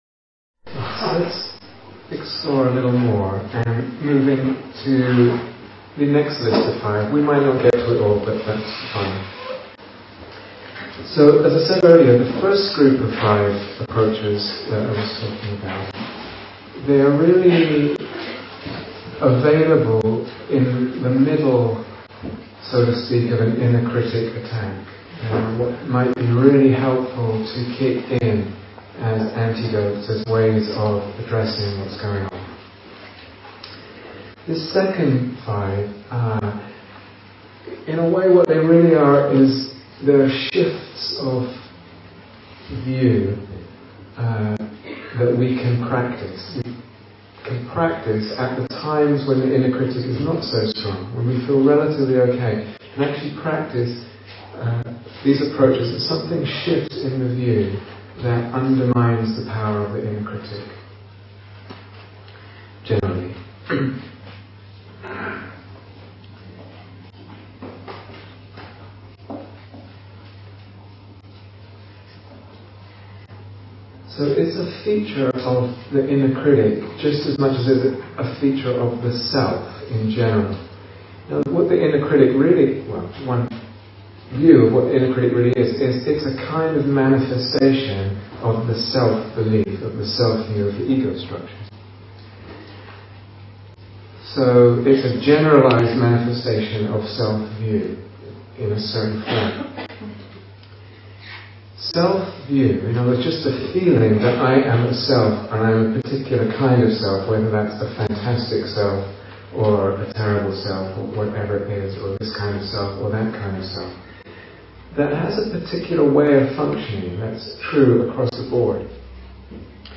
AI-enhanced audio (noise reduced)
Day Retreat, London Insight 2010